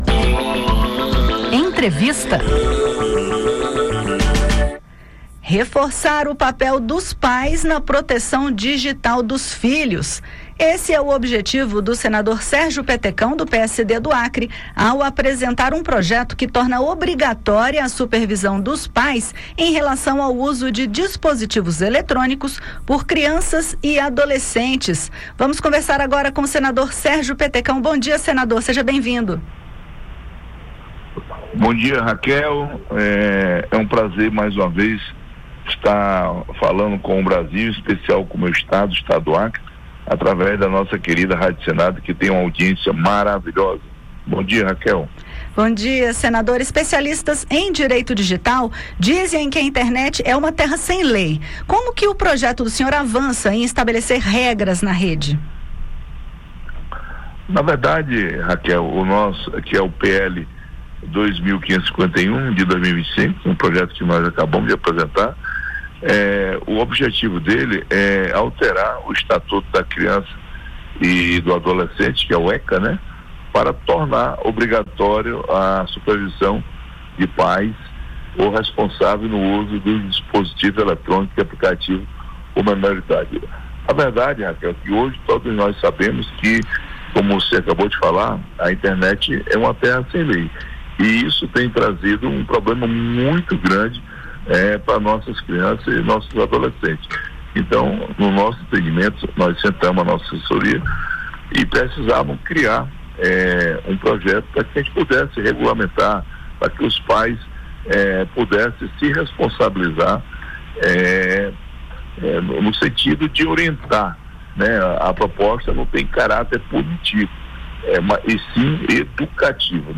Em entrevista ao programa Conexão Senado, da Rádio Senado, o senador Sérgio Petecão (PSD-AC), que é o autor do projeto, destaca o objetivo de reforçar o papel dos pais na proteção digital dos filhos e incentivar a consciência de um uso mais racional das redes sociais por parte dos jovens. O senador afirma que é possível avançar no estabelecimento de regras na rede e, em consequência, isso pode ajudar a deter atos violentos dentro de escolas usando redes sociais.